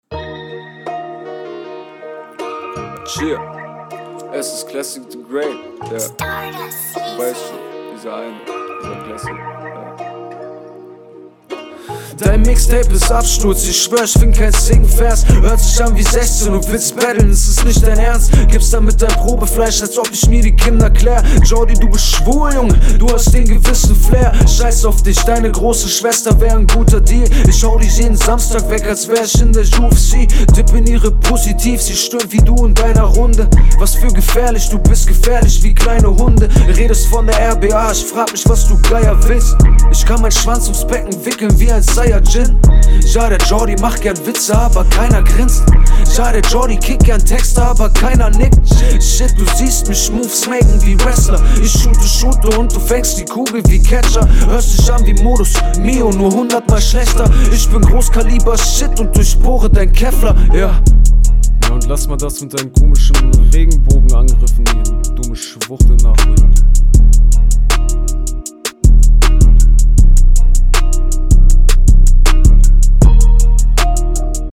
Runde fand ich ganz gut Stil und Stimmeinsatz erinnert mich an anfang 00er, Eko, kool …